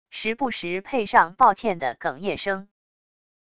时不时配上抱歉的哽咽声wav音频